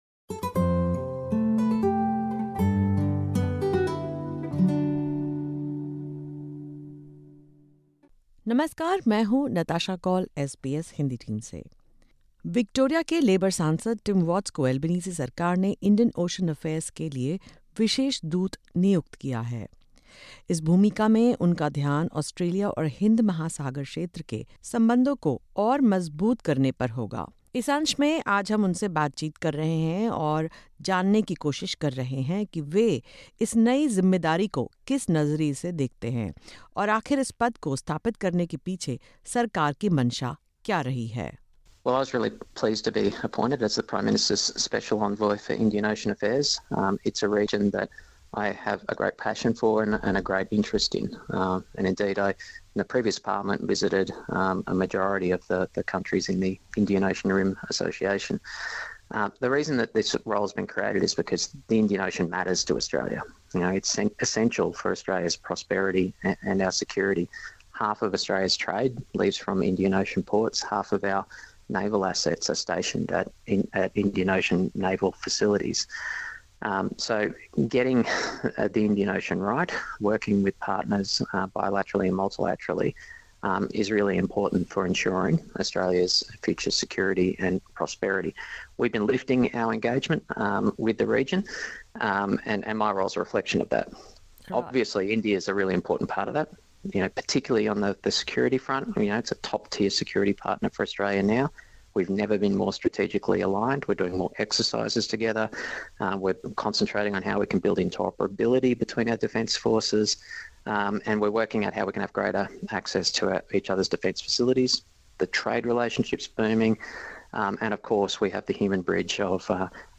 Tim Watts, the Labor MP for Gellibrand, one of Australia’s most culturally diverse electorates, has been appointed by the Prime Minister as the Special Envoy for Indian Ocean Affairs. In this podcast, Watts, a former Assistant Foreign Minister, spoke about his upcoming visit to India this month and how he envisions his new role in strengthening ties with partners across the Indian Ocean.